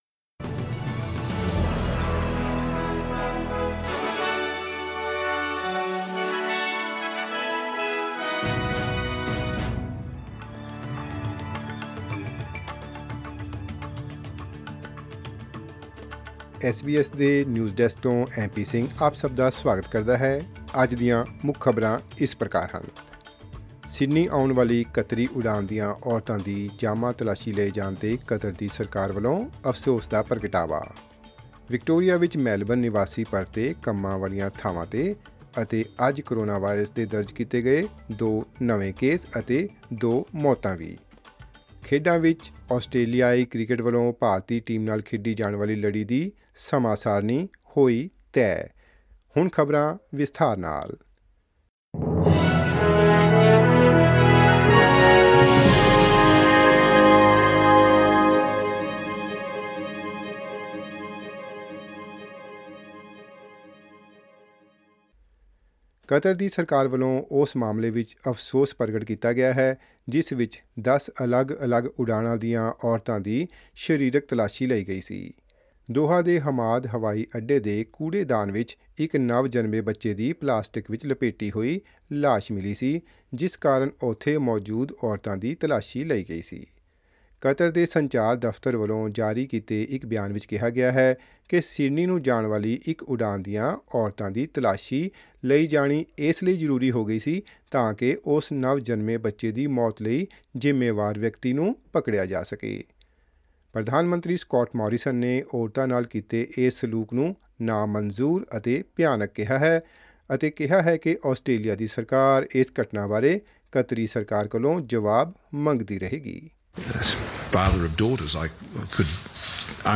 SBS Punjabi News 28 Oct: Thousands of Melburnians back at work; two new coronavirus cases and deaths